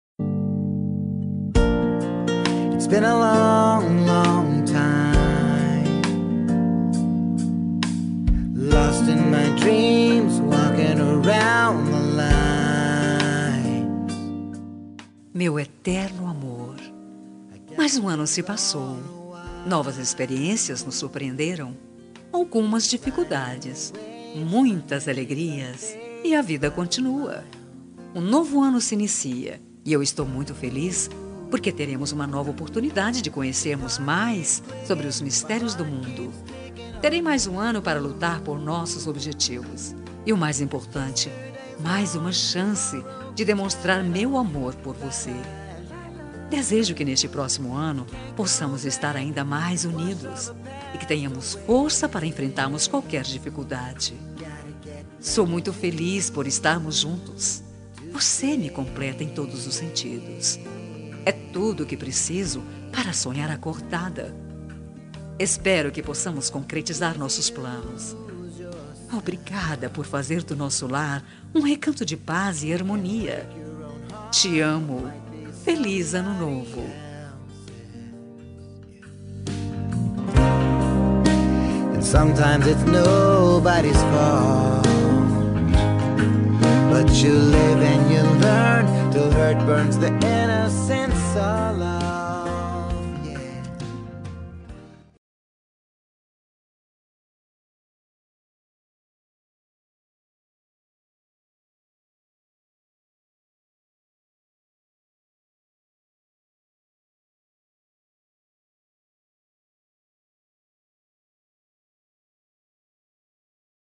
Ano Novo – Romântica – Voz Masculina – Cód: 6427